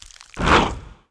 Index of /App/sound/monster/skeleton_wizard
attack_act_1.wav